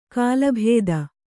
♪ kālabhēda